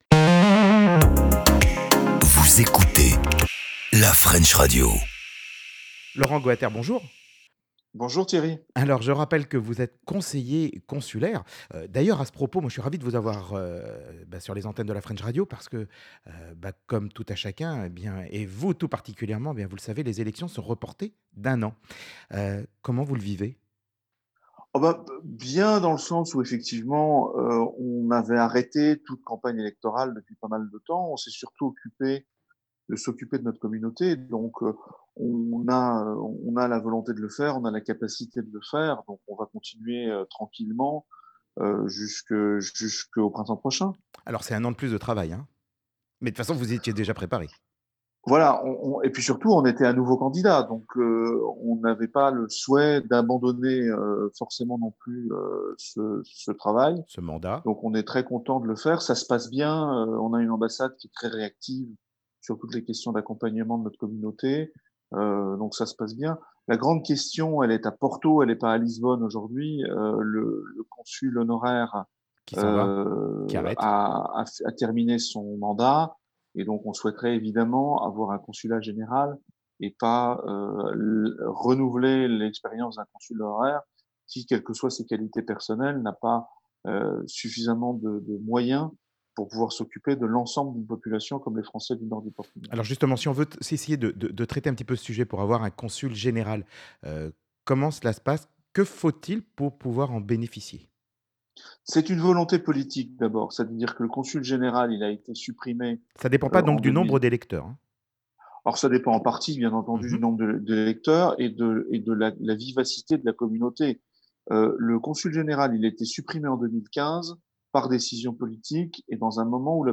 Laurent Goater est conseiller consulaire et on fait avec lui un point sur la situation sanitaire et règlementaire au Portugal.
Ainsi les thèmes abordés lors de cette interview sont les suivants :